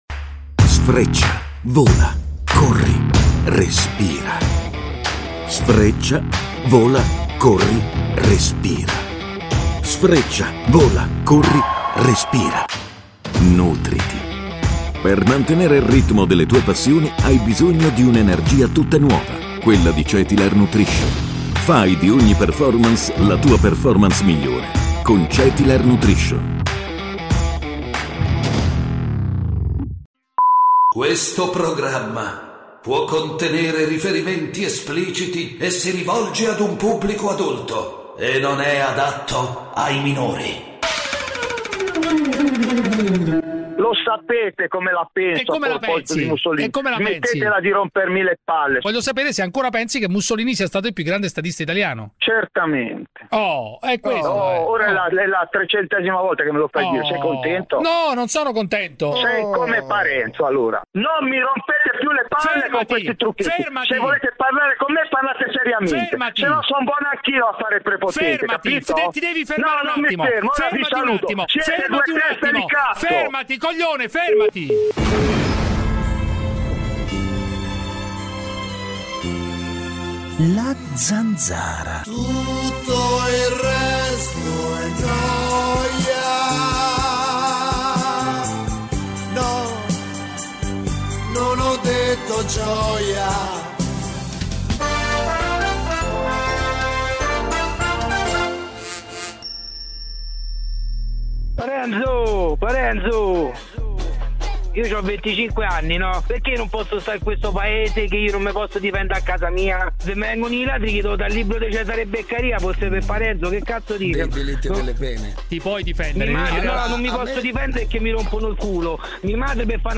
Giuseppe Cruciani con David Parenzo conduce "La Zanzara", l'attualità senza tabù, senza censure, senza tagli alle vostre opinioni. Una zona franca per gli ascoltatori, uno spazio nemico della banalità e del politicamente corretto, l'arena dove il primo comandamento è parlare chiaro.
… continue reading 1998 에피소드 # Italia Attualità # News Talk # Notizie # Radio 24